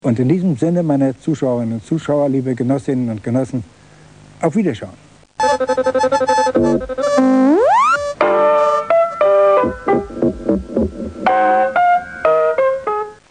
Fernsehkommentator (1918-2001)